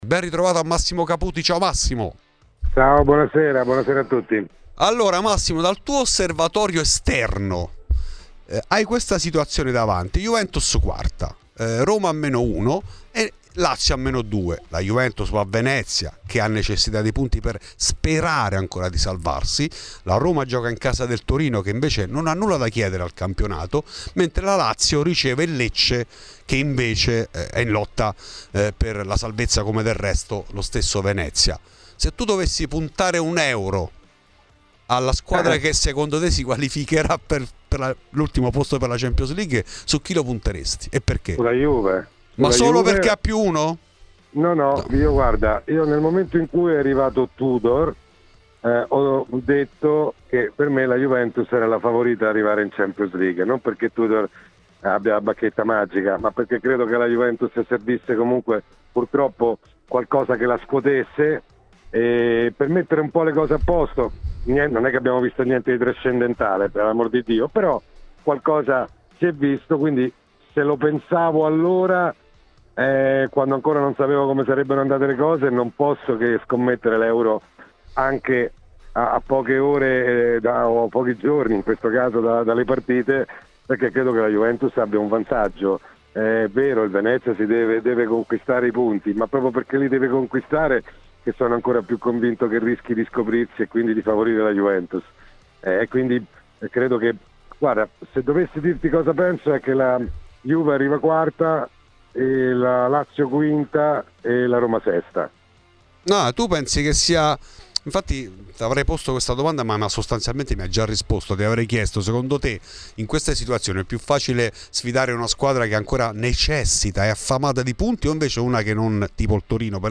Sul fronte del mercato, si fanno inomi di Osimhen e Tonali. Di questo ed altro ha parlato in ESCLUSIVA a Fuori di Juve, Massimo Caputi.